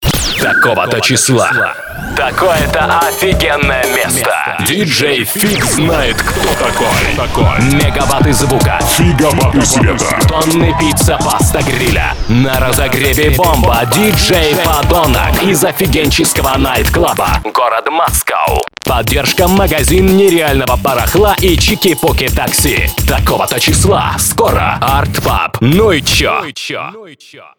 Обработка голоса диктора